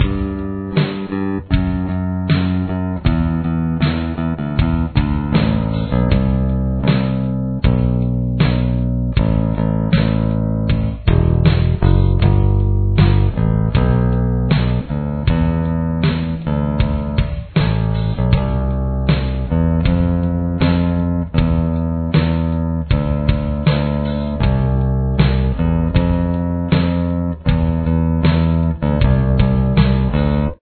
Verse Riff
Bass